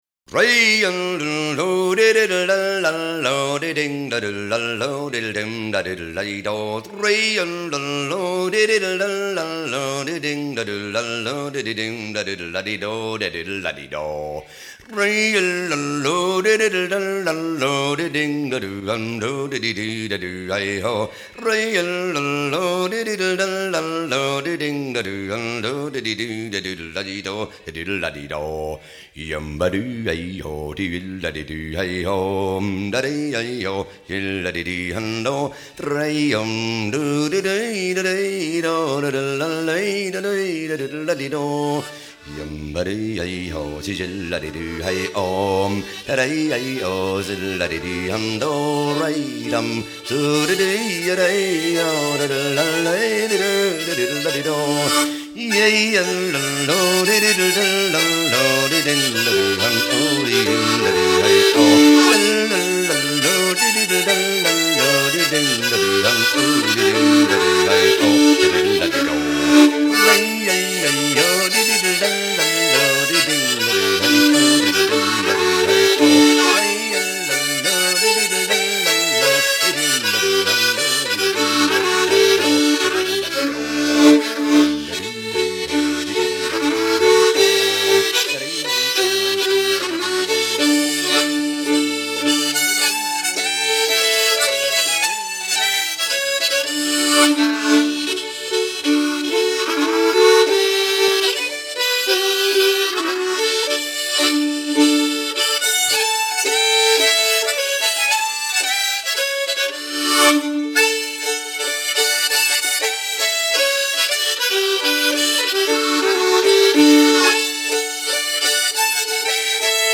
fluid style
floating European swing